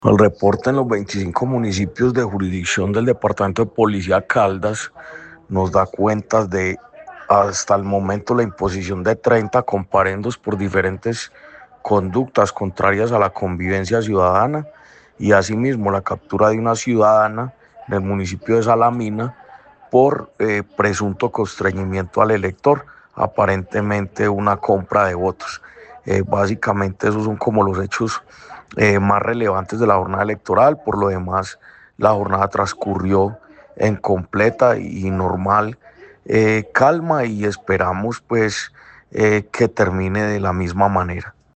El secretario de Gobierno de Caldas, Jorge Andrés Gómez Escudero, entregó un balance oficial sobre el desarrollo de la jornada electoral en los 25 municipios que integran la jurisdicción del Departamento de Policía Caldas.
Jorge-Andres-Gomez-Escudero-secretario-de-Gobierno-de-Caldas-Balance-jornada-electoral.mp3